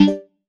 Simple Cute Alert 28.wav